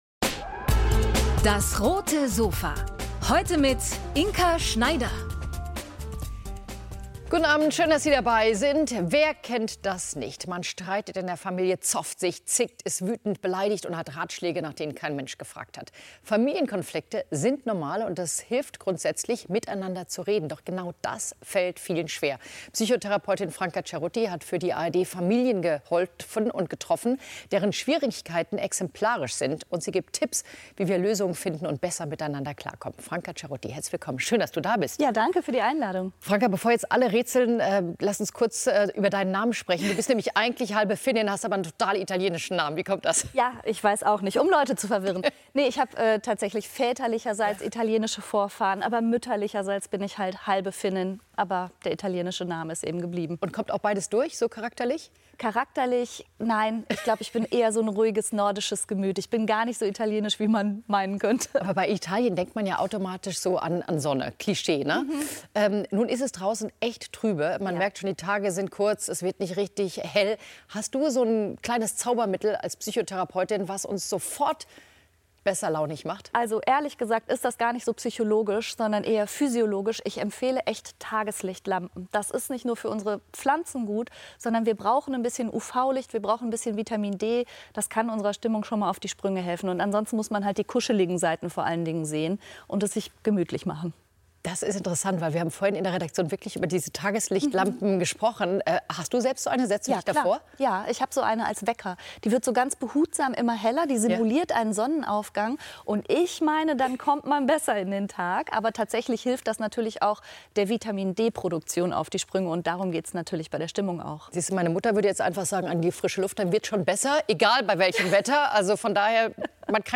Rote Sofa ~ DAS! - täglich ein Interview Podcast